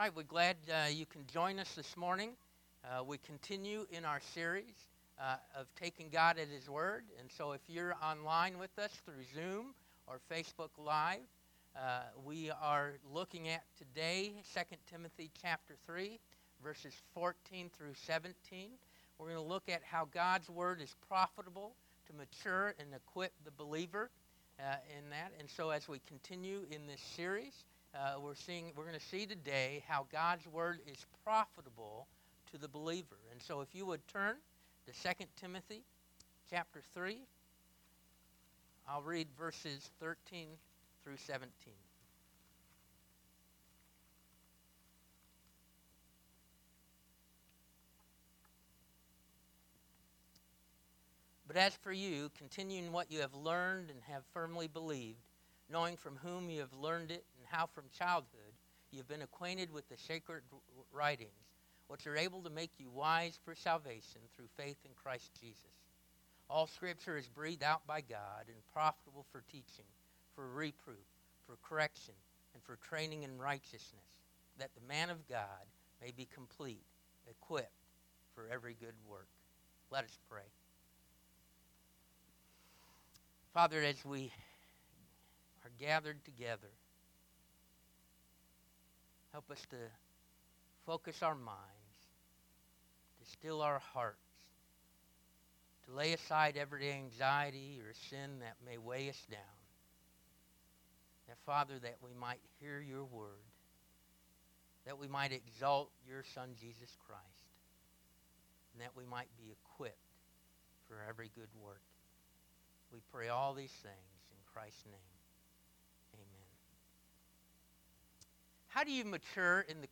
North Stafford Baptist Church Sermon Audio The Wisdom of God is the path to wholeness James 1:2-11 Play Episode Pause Episode Mute/Unmute Episode Rewind 10 Seconds 1x Fast Forward 30 seconds 00:00 / Subscribe Share